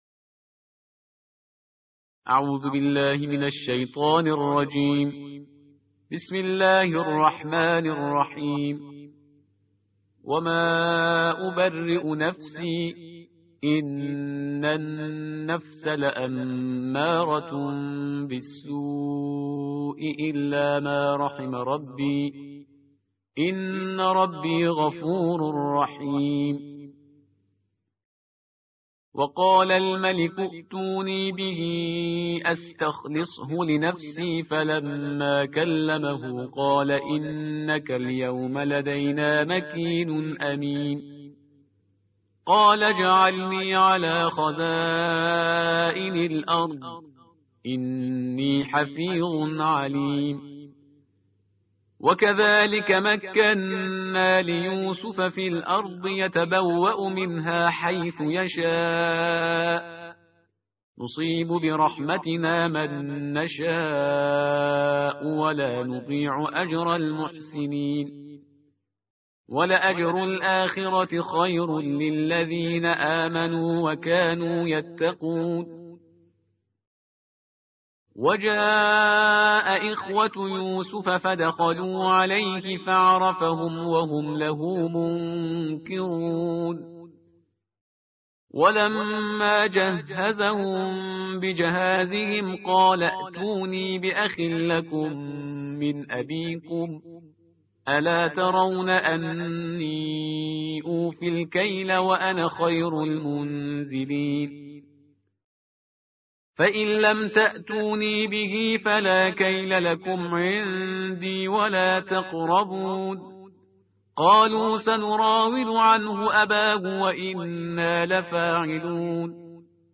ترتیل جزء سیزده قرآن کریم/استاد پرهیزگار